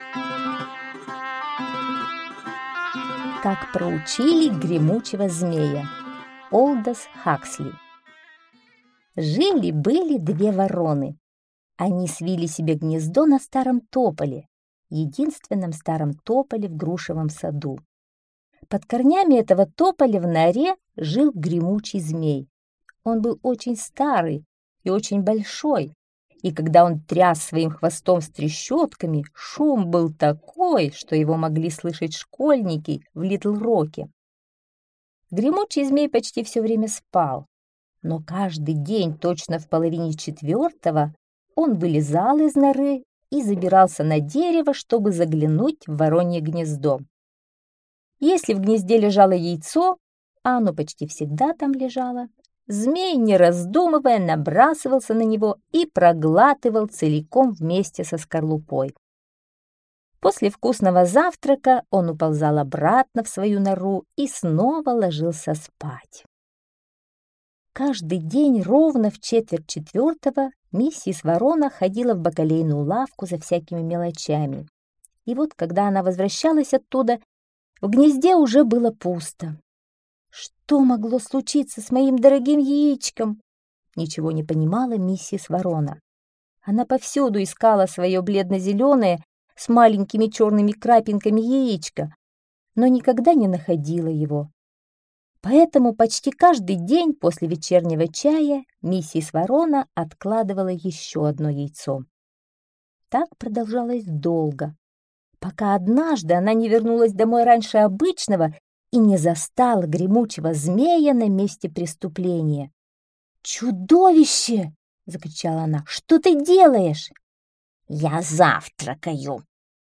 Как проучили гремучего змея - аудиосказка Хаксли - слушать